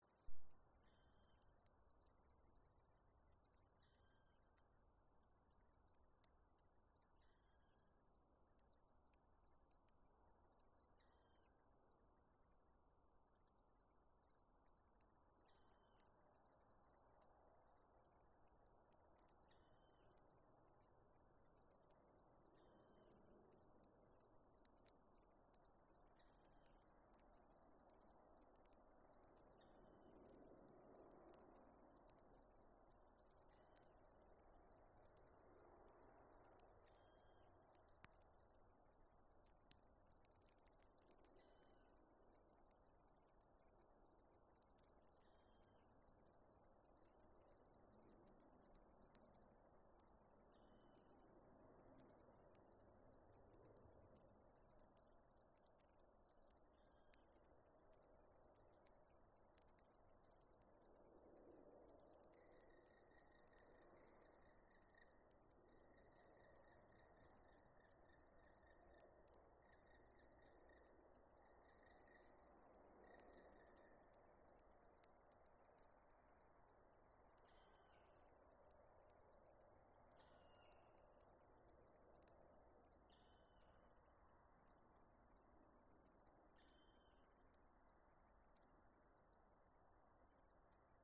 Melnā dzilna, Dryocopus martius
Administratīvā teritorijaViļakas novads
StatussDzirdēta balss, saucieni